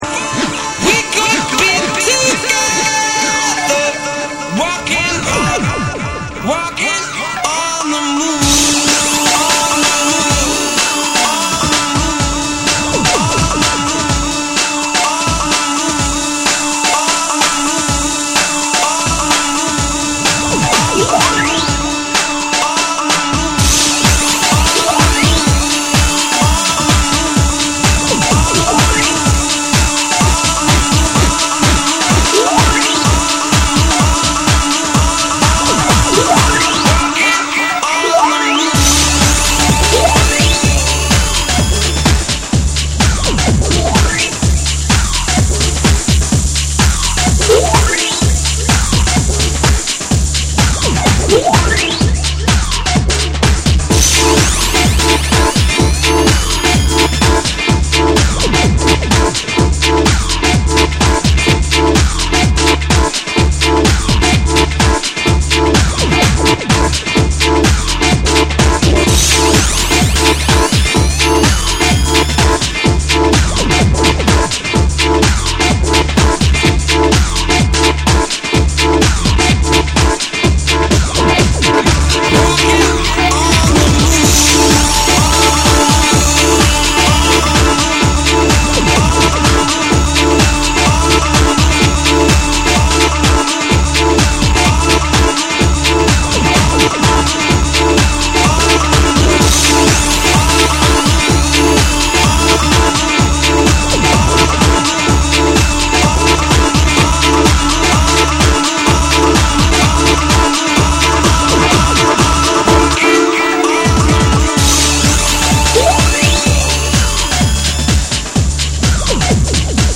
オリジナルの魅力を活かしつつ、ヒップホップ〜ブレイクビーツ〜ハウスの視点でアップデートされたクロスオーバーな一枚。
NEW WAVE & ROCK / BREAKBEATS / TECHNO & HOUSE